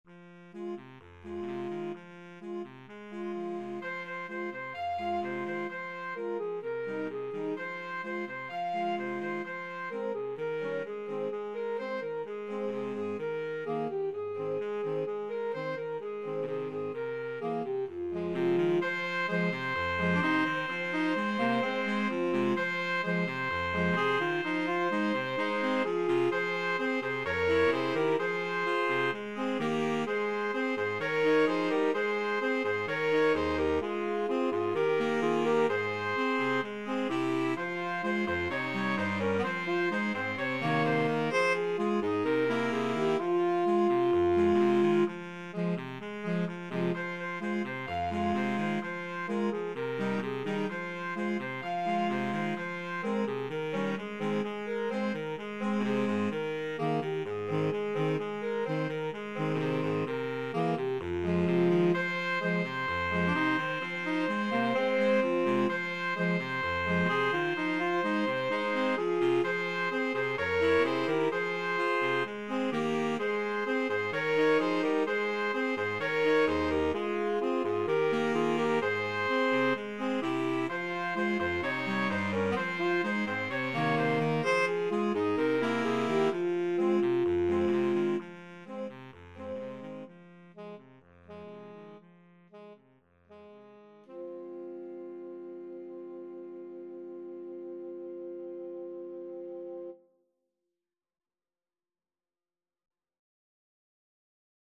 Free Sheet music for Saxophone Quartet
Alto SaxophoneTenor Saxophone 1Tenor Saxophone 2Baritone Saxophone
This upbeat playful children's song has lines about dancing the Horah, Spinning Dreidels ( Shining Tops) eating latkes, lighting the candles and singing happy songs.
F minor (Sounding Pitch) (View more F minor Music for Saxophone Quartet )
Allegro e cantabile = c. 128 (View more music marked Allegro)
4/4 (View more 4/4 Music)
Saxophone Quartet  (View more Intermediate Saxophone Quartet Music)
World (View more World Saxophone Quartet Music)
chanukah_SAXQ.mp3